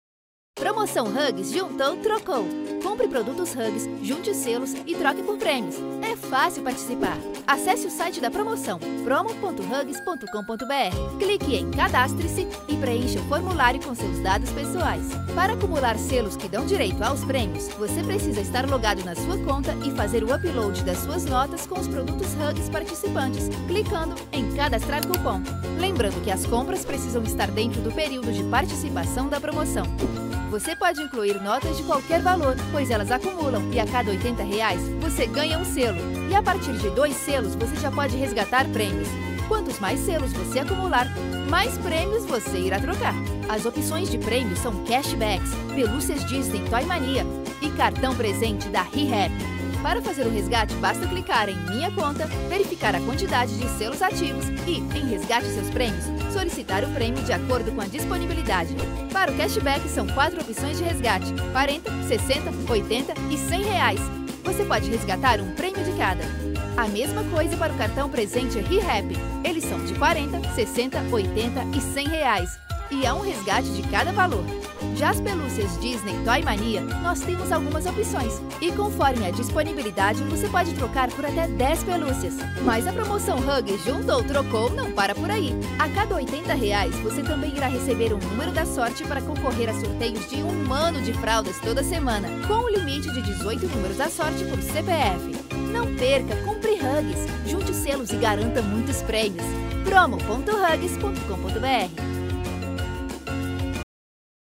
Promos
My vocal range spans ages 13 to 40.
HighMezzo-Soprano